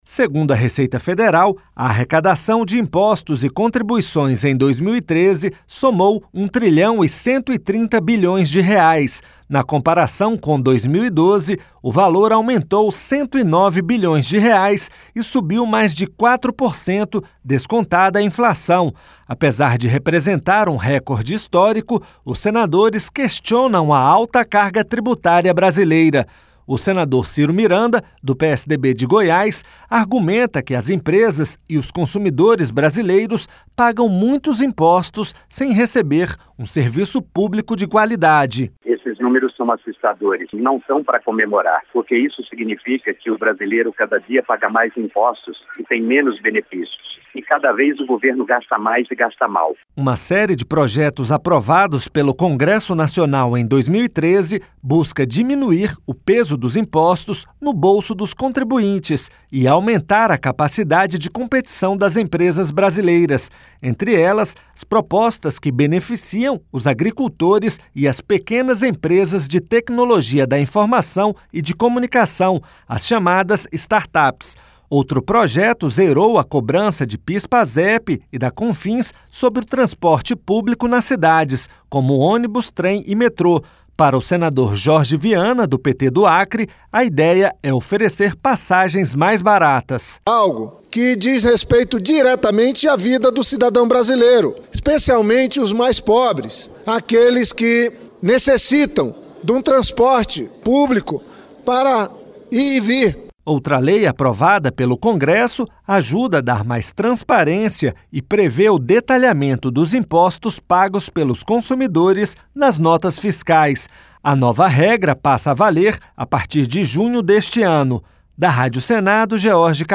O senador Cyro Miranda, do PSDB de Goiás, argumenta que as empresas e os consumidores brasileiros pagam muitos impostos sem receber um serviço público de qualidade.